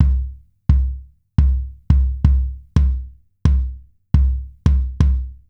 Вот вэвка бочки Вложения kick.wav kick.wav 950,6 KB · Просмотры: 220